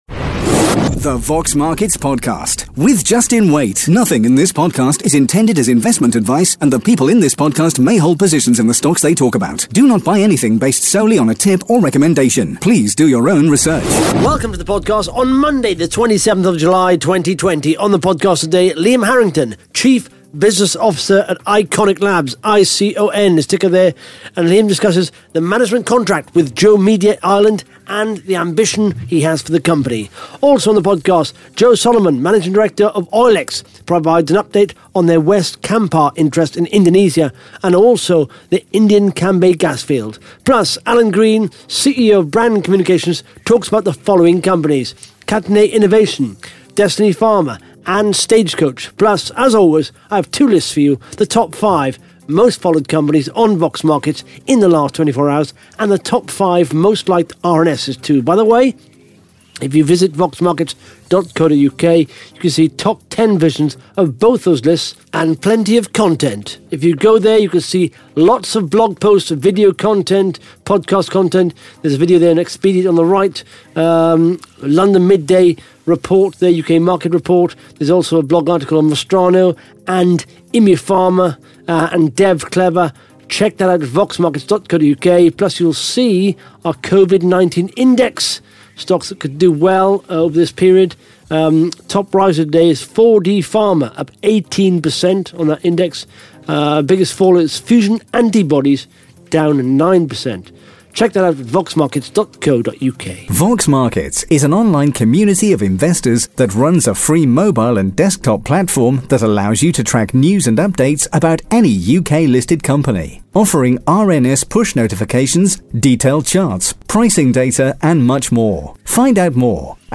(Interview starts at 16 minutes 29 seconds)